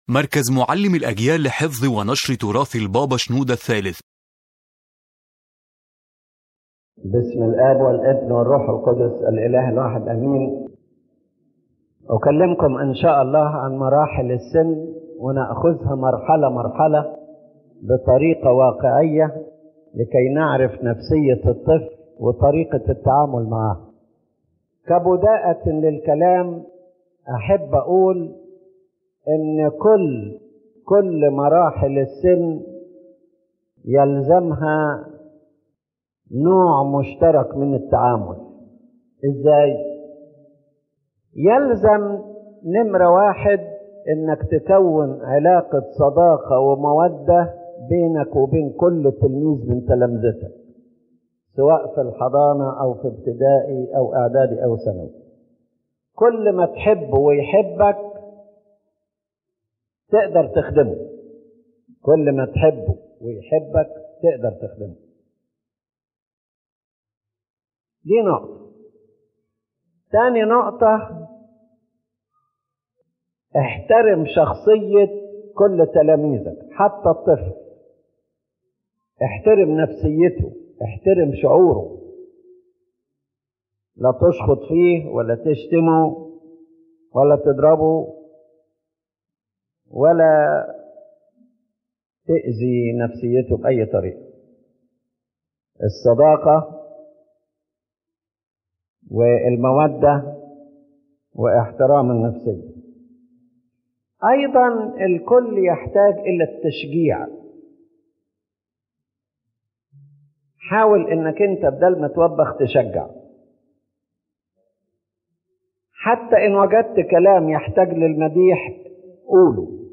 The lecture focuses on understanding the nature of the childhood stage from a psychological and spiritual perspective, and how to deal with the child in a correct and constructive way, especially within church service, to ensure healthy growth in faith, behavior, and personality.